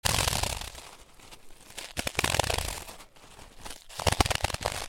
ghost_idle.ogg